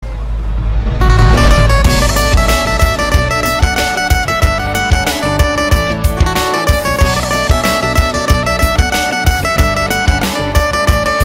Tik Tok Ringtones